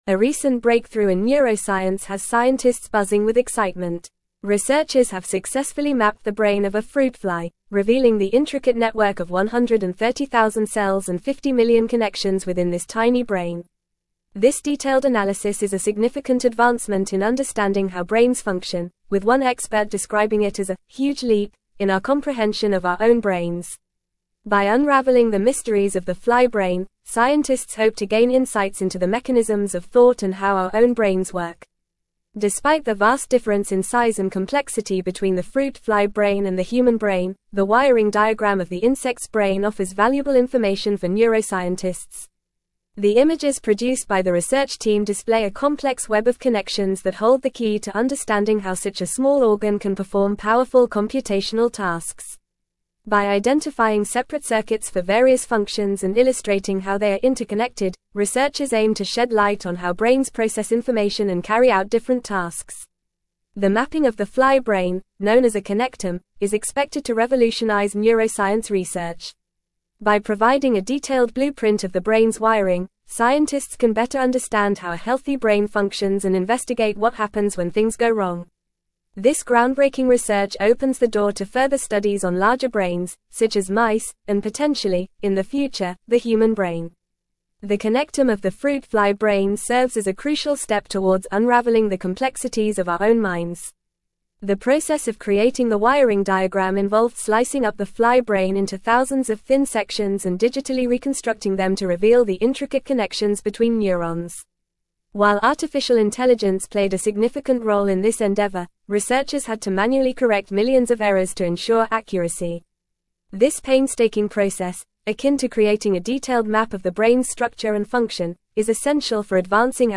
Fast
English-Newsroom-Advanced-FAST-Reading-Groundbreaking-Neuroscience-Discovery-Mapping-a-Flys-Brain-Connections.mp3